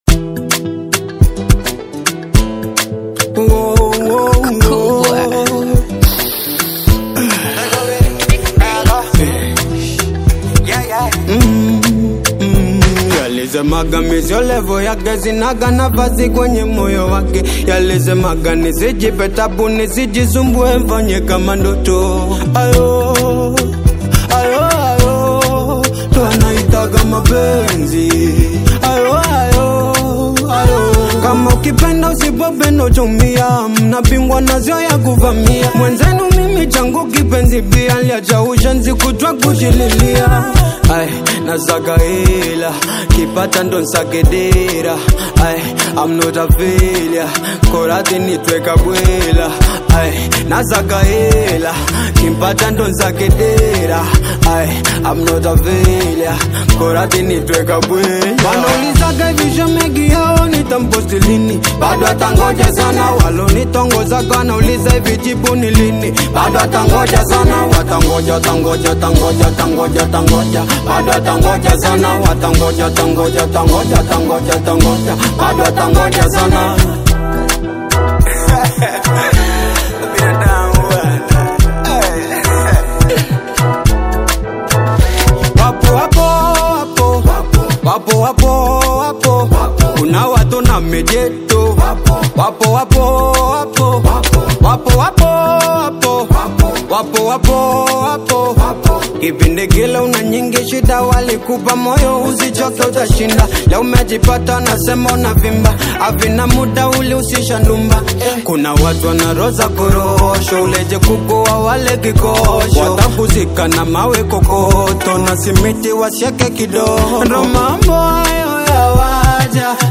East African male superstar